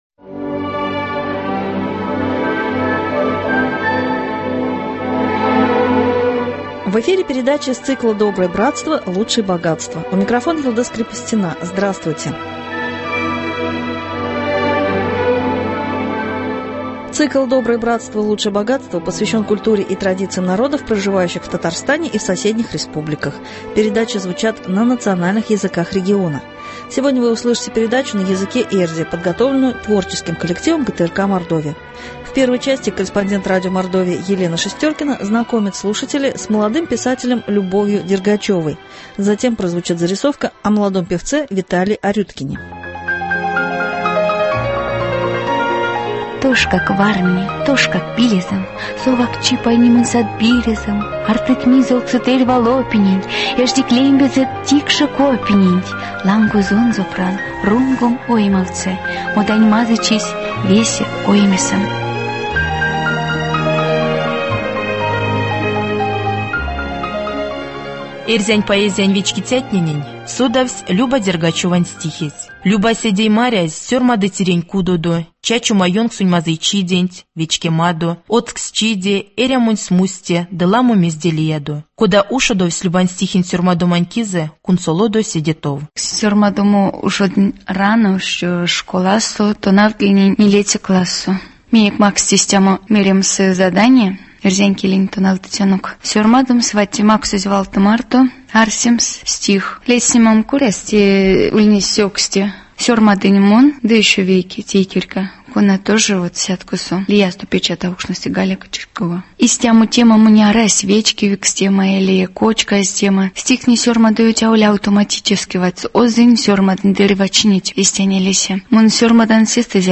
Сегодня вы услышите передачу на языке эрьзя, подготовленную творческим коллективом ГТРК «Мордовия».